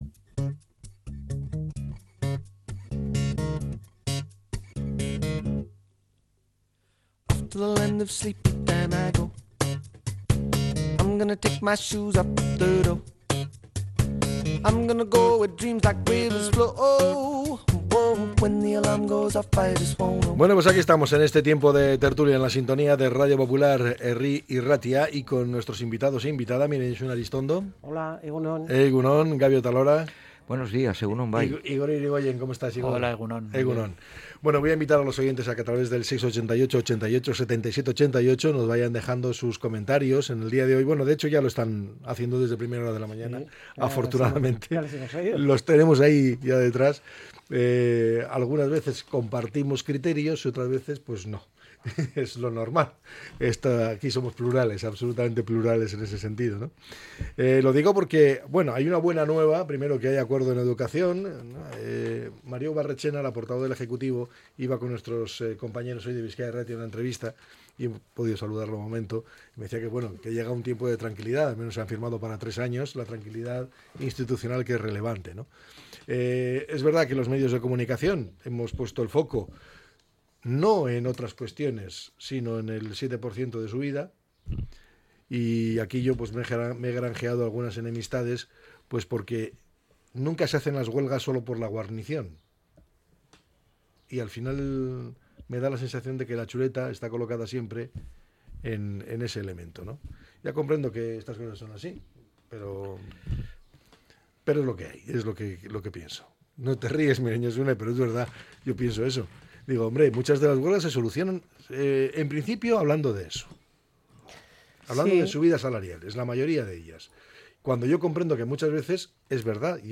La tertulia 12-05-25.